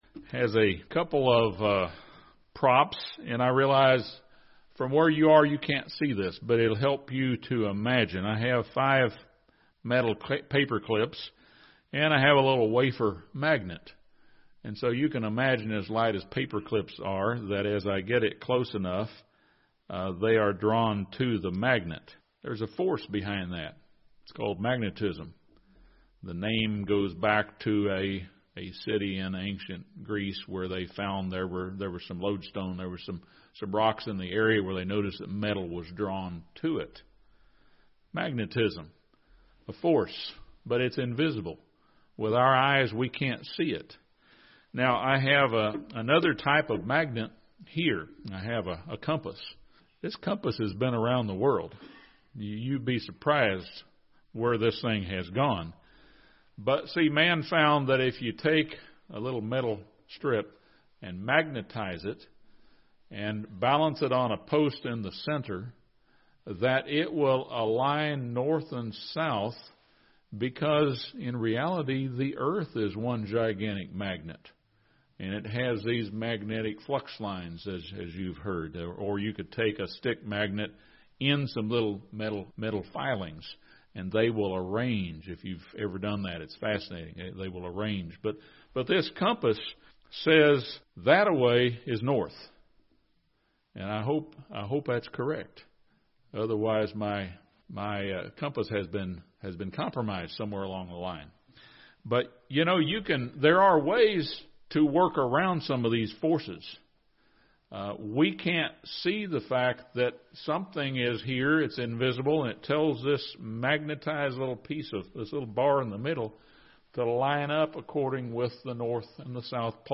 In similar manner, an invisible spiritual force known as temptation seeks to influence us every minute of every day. This sermon discusses the temptations of Adam & Eve and contrasts them with the temptations of Jesus Christ. The second Adam was victorious and set the pattern for us to successfully resist the tempter's influence.